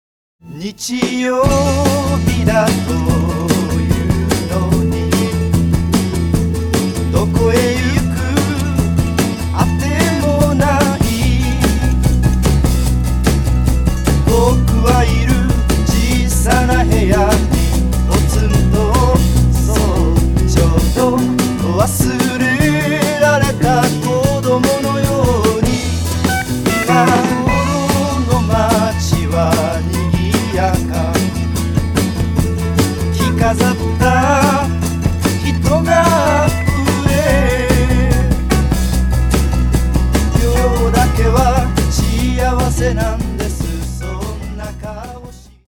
ジャンル：フォーク/ロック